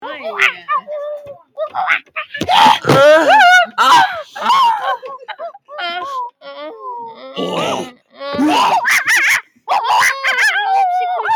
Monkeys Going Wild Bouton sonore